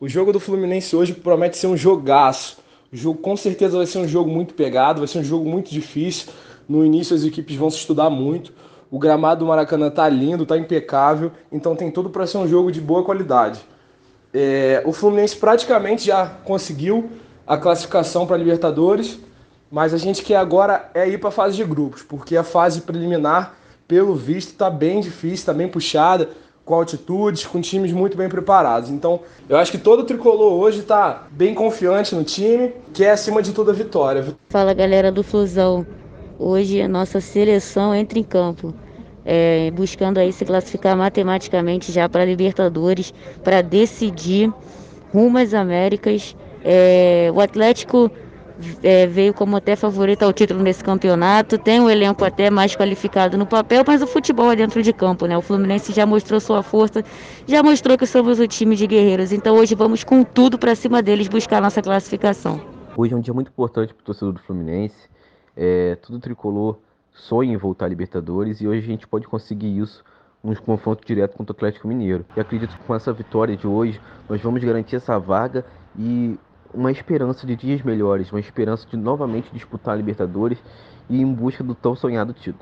TORCEDORES-online-audio-converter.com_.mp3